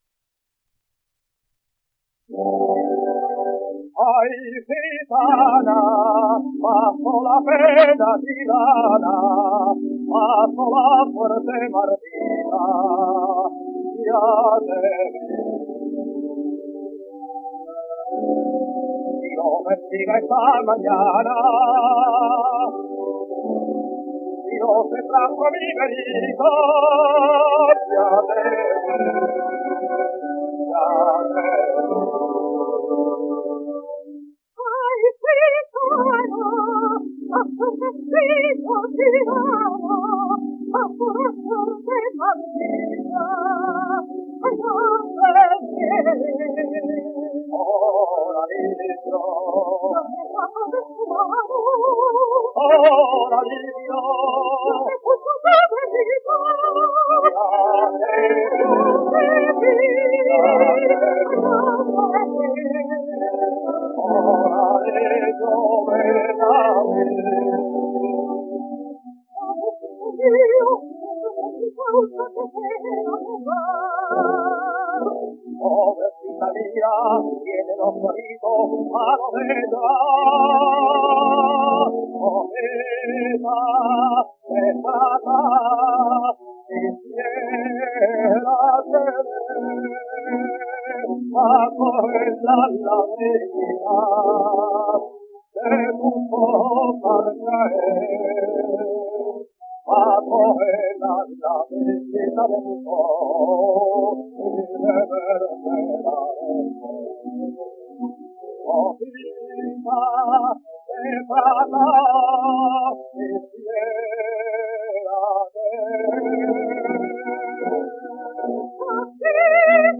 La Reina Mora: Dúo de la Cárcel (sonido remasterizado)
1 disco : 78 rpm ; 27 cm Intérpretes